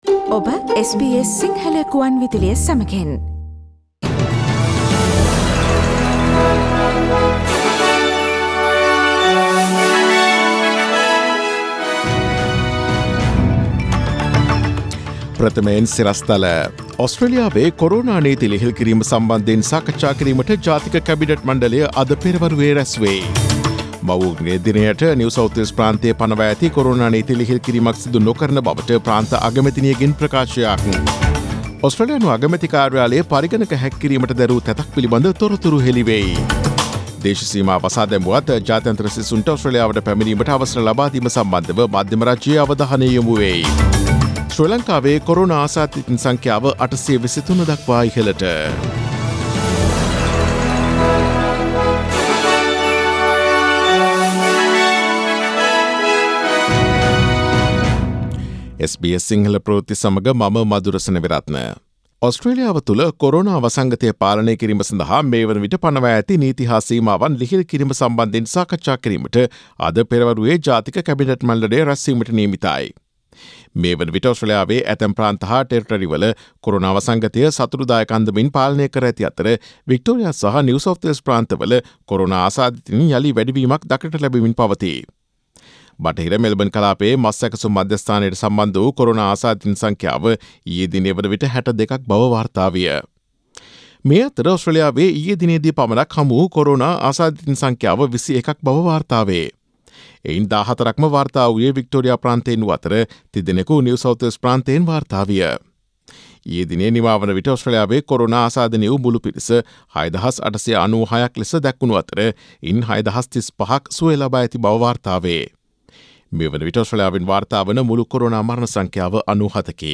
Daily News bulletin of SBS Sinhala Service: Friday 08 May 2020